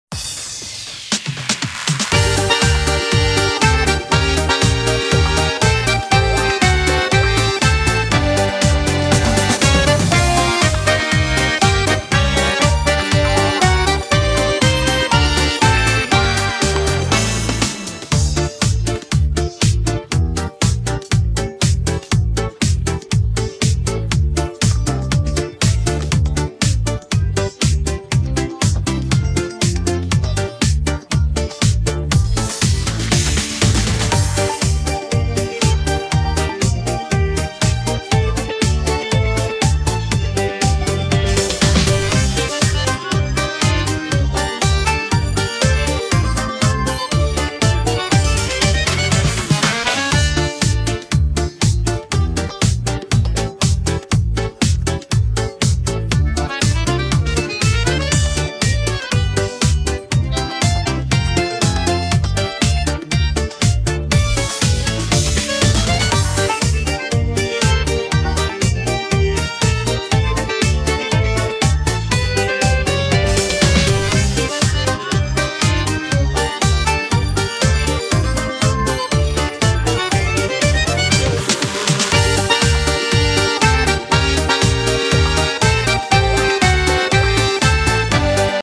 • Теги: минусовка
Минусовка (мастер задавка).